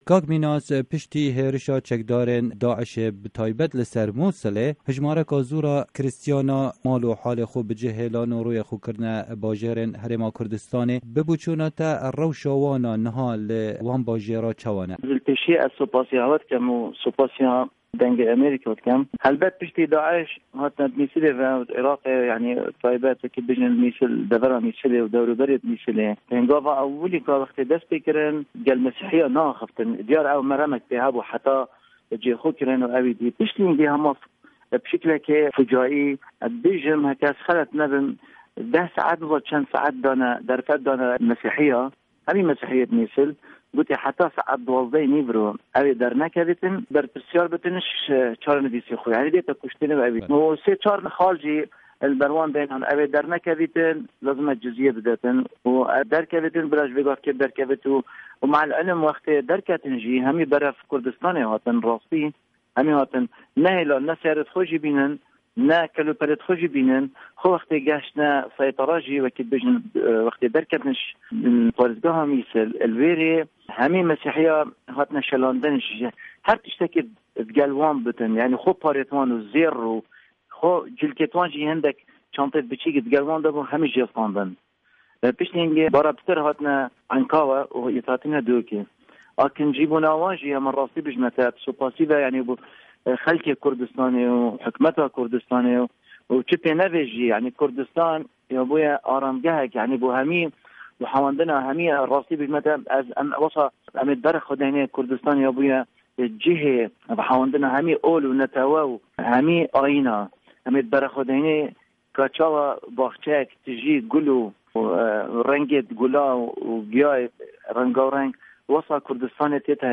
Bêtir agahî di dirêjahiya vê hevpeyvînê de.